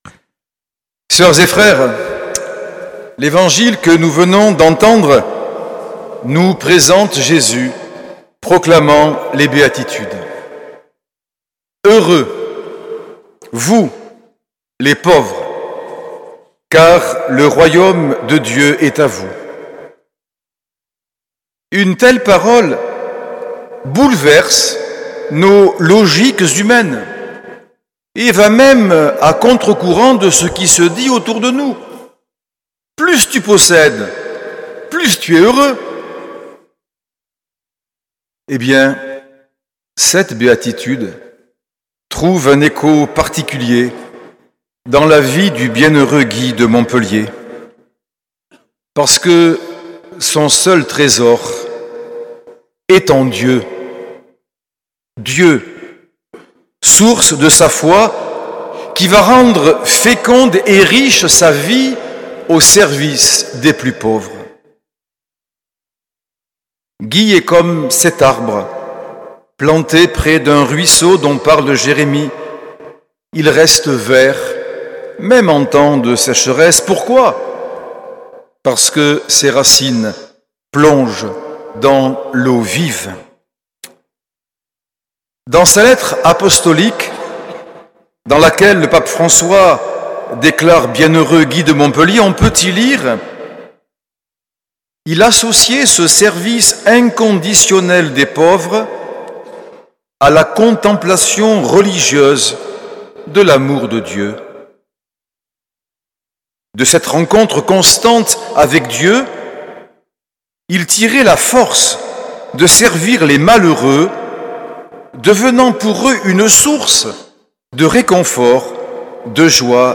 Homélie de Monseigneur Norbert TURINI, le 16 février 2025, messe à la mémoire du bienheureux Guy de Montpellier, 6ème dimanche du temps ordinaire
L’enregistrement retransmet l’homélie de Monseigneur Norbert TURINI.